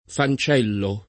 DOP: Dizionario di Ortografia e Pronunzia della lingua italiana
fancello